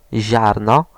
ʑ ź, z(i)[2]
ziarno vision, azure[4]